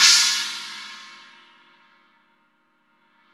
CYM XCHINA16.wav